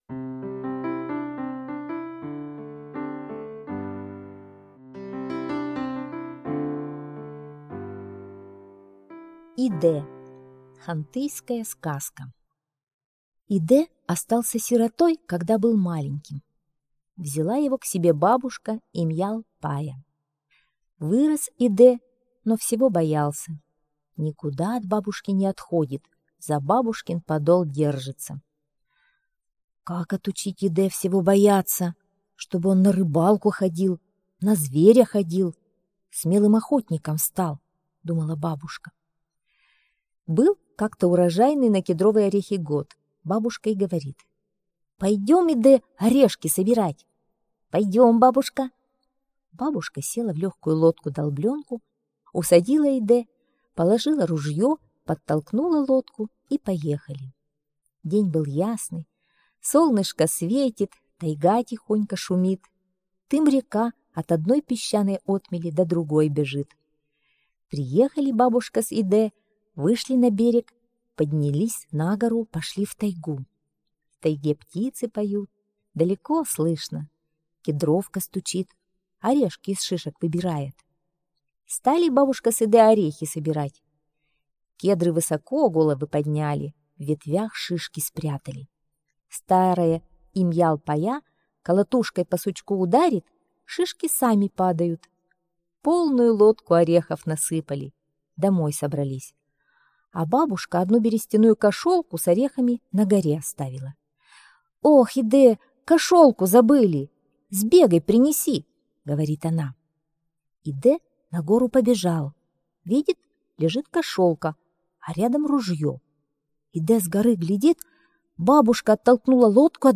Идэ - хантыйская аудиосказка - слушать онлайн